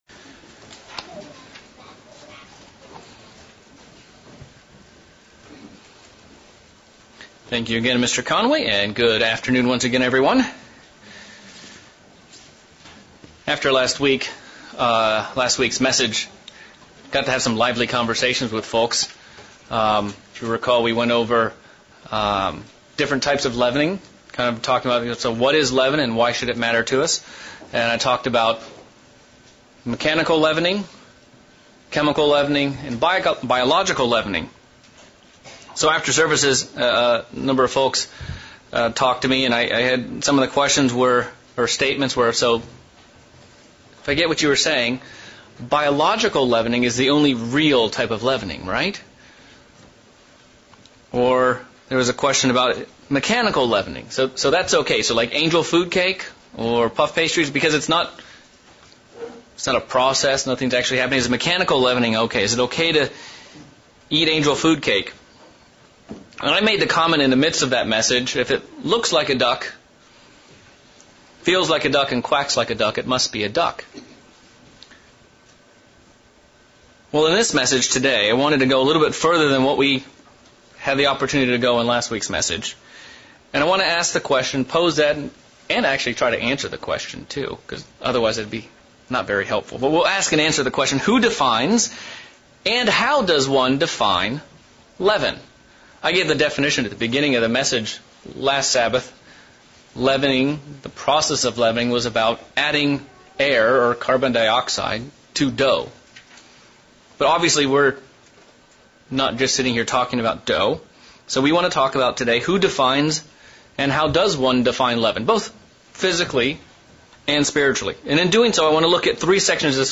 Part 2 of a sermon series on Leavening. This sermon focuses on three sections of Jesus' own teachings to discover God's expectations for us when it comes to dealing with leaven – both physical and spiritual.